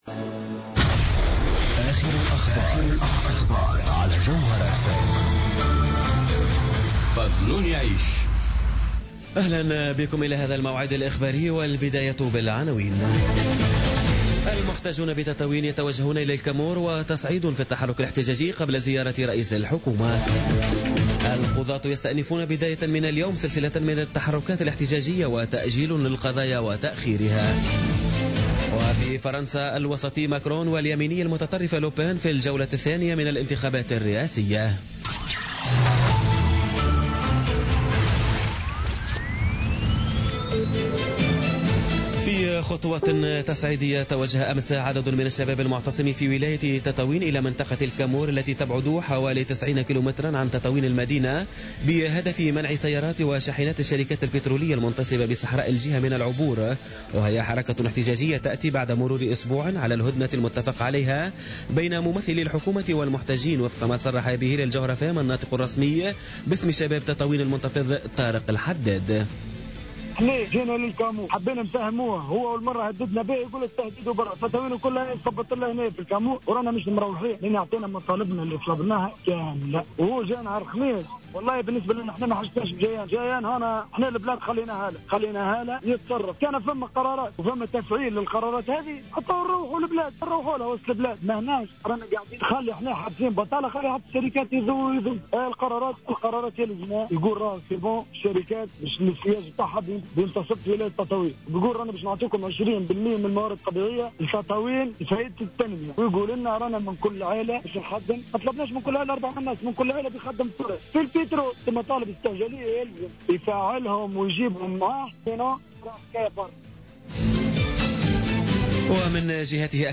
نشرة أخبار منتصف الليل ليوم الإثنين 24 أفريل 2017